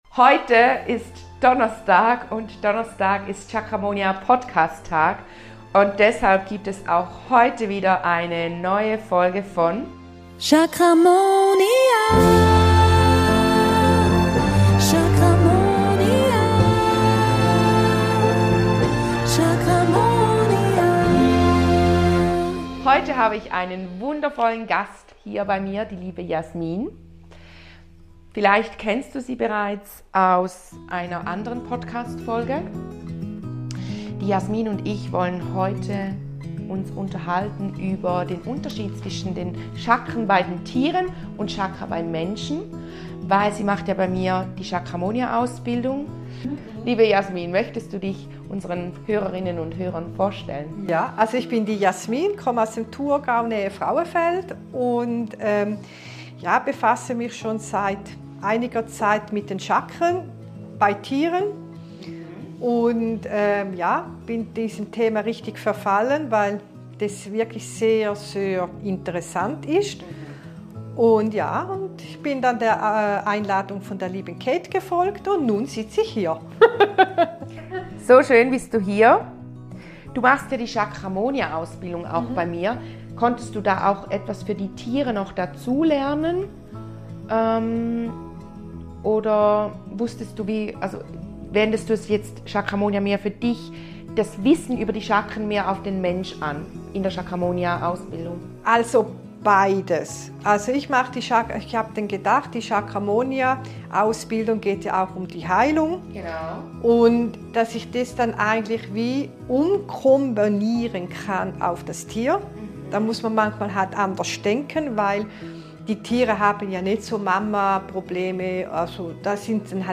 mit einem Video-Interview